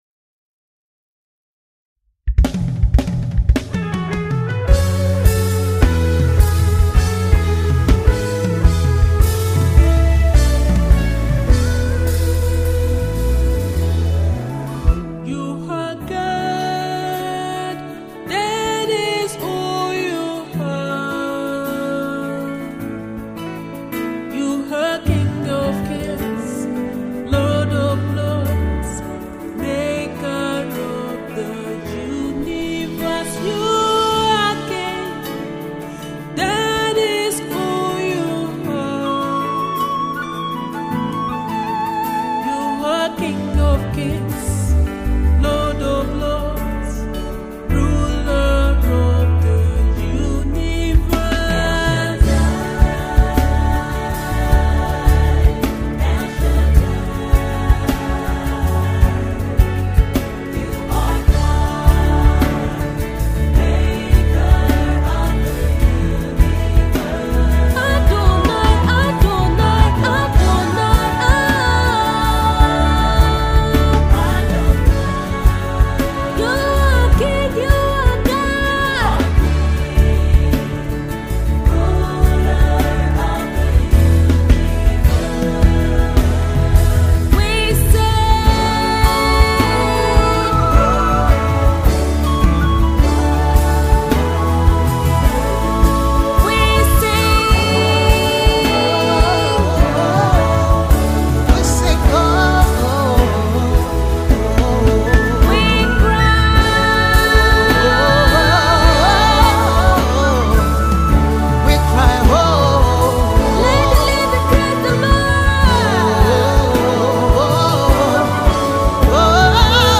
Inspirational, worship, praise, soul and folk music.